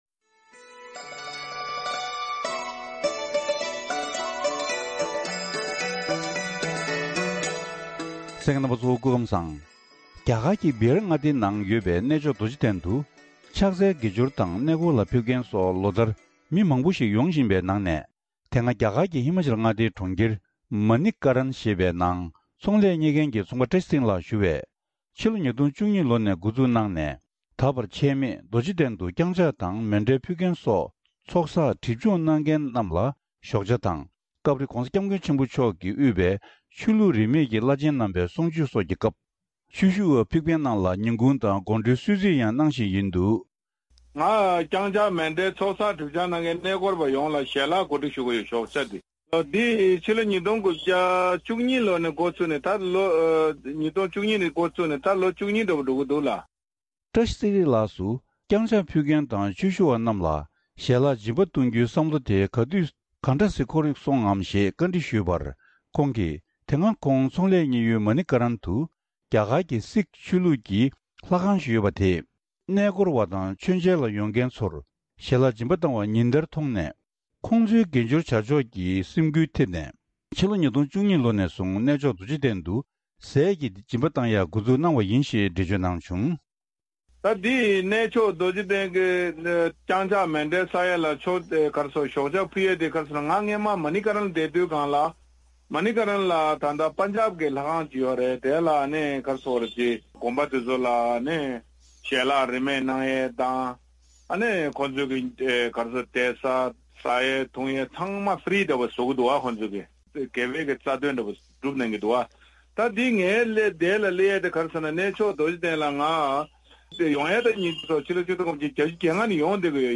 ཁོང་ལ་བཀའ་འདྲི་ཕྱོགས་སྒྲིག་ཞུས་པ་ཞིག་གསན་རོགས་གནང་།